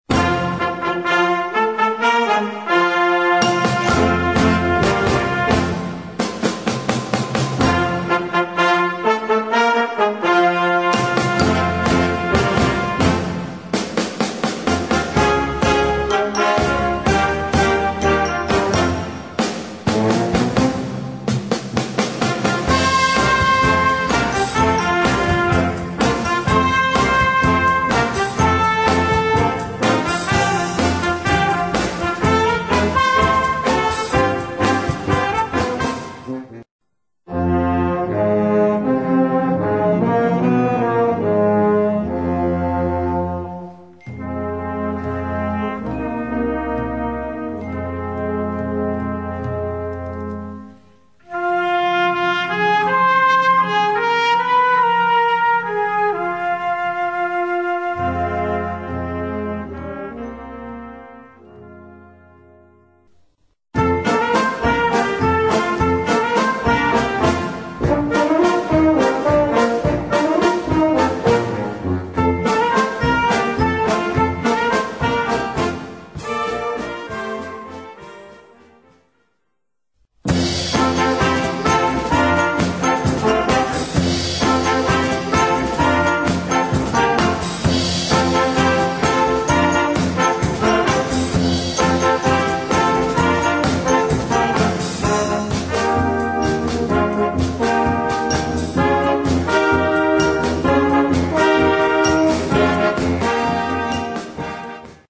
Gattung: Flexibles Ensemble
Besetzung: Blasorchester
Zuerst gibt es den kräftigen Gorilla Marsch.